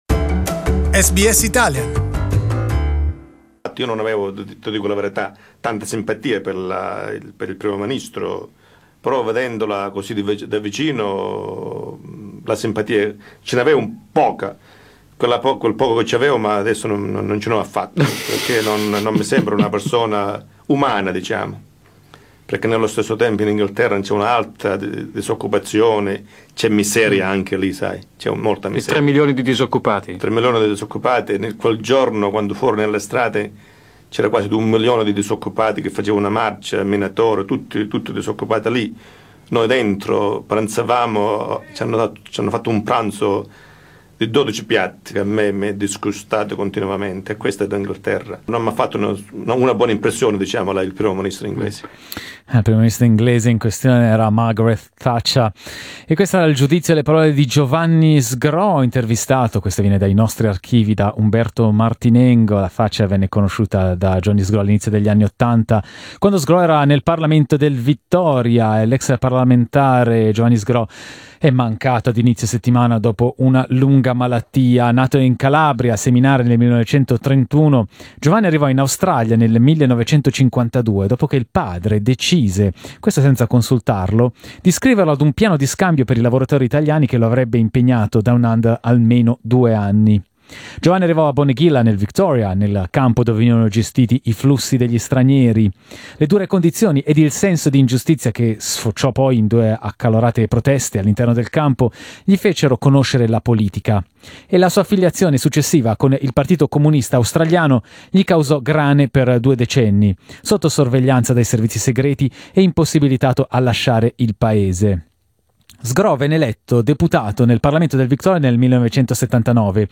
In the audio interview in Italian, former politician Carlo Carli remembers his life and analyses the importance of his legacy.